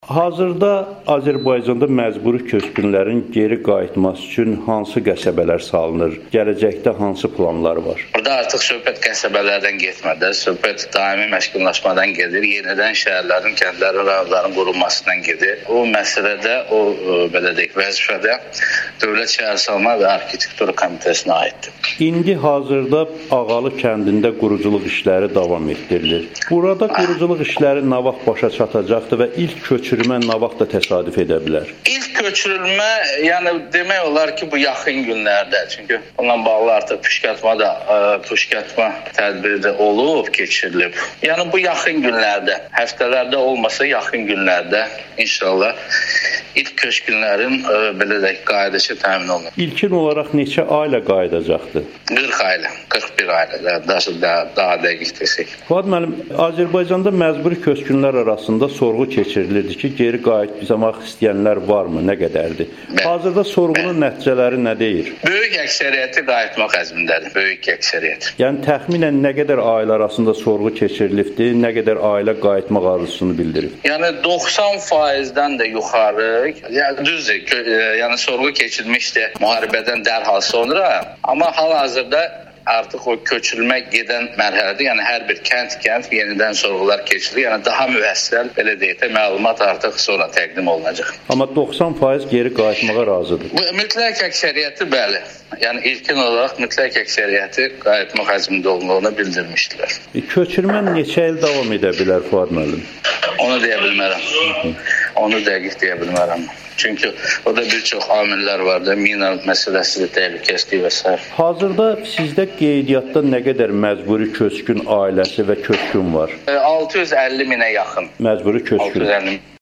Azərbaycan Qaçqınların və Məcburi Köçkünlərin İşləri üzrə Dövlət Komitəsinin sədr müavini Fuad Hüseynov Amerikanın Səsinə müsahibəsində bildirib ki, yaxın günlərdə azad edilmiş ərazilərdə daimi məskunlaşacaq insanların ilk köçürülməsi olacaq.